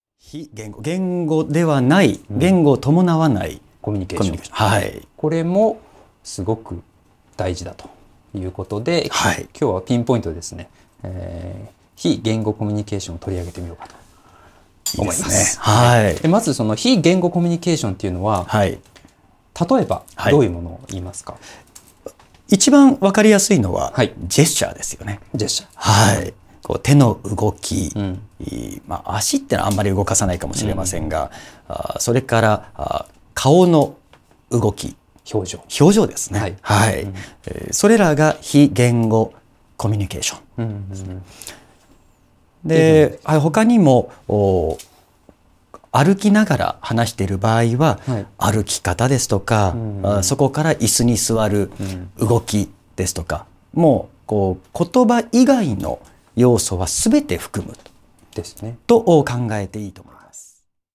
それでは、音声講座でお会いしましょう。